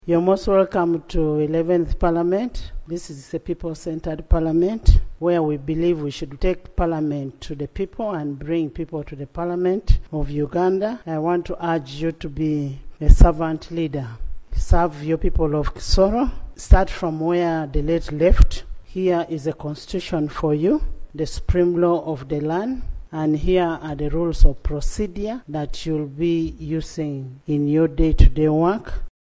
While chairing the sitting on Tuesday, 26 November 2023, Speaker Anita Among congratulated Ngabirano on her successful election and urged her to support its mission of being a people-centred institution.
AUDIO Speaker Among
Speaker Anita Among welcomes new Kisoro District Woman MP - Hon. Grace Ngabirano.mp3